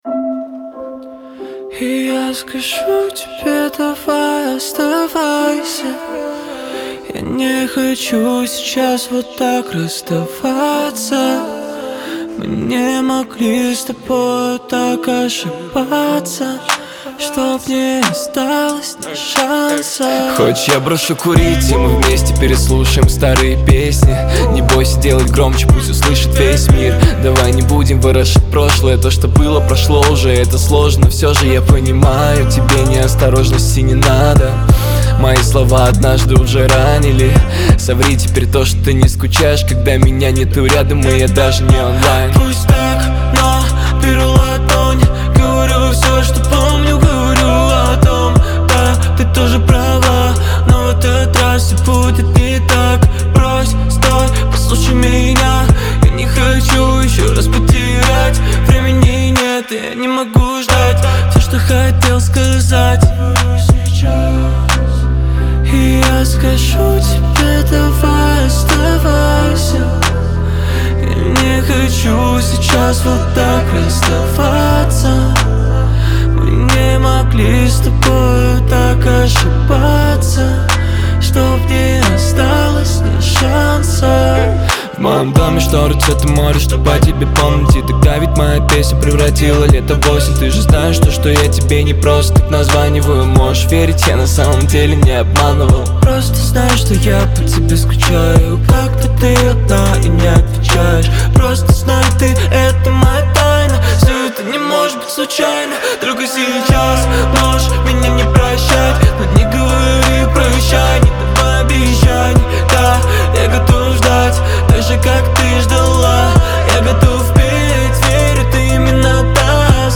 используя запоминающиеся мелодии и ритмичные биты.